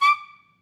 Clarinet
DCClar_stac_D5_v3_rr2_sum.wav